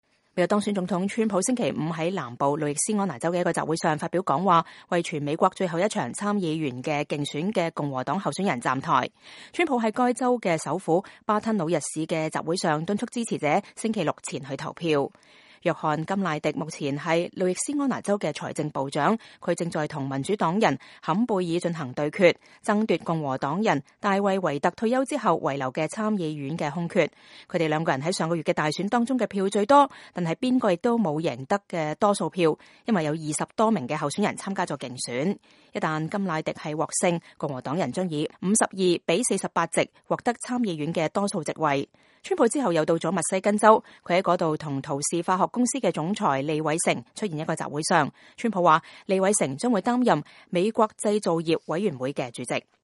美國當選總統唐納德·川普星期五在南部路易斯安那州的一個集會上發表講話，為全美最後一場參議員競選的共和黨候選人站台。